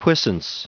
Prononciation du mot puissance en anglais (fichier audio)
Prononciation du mot : puissance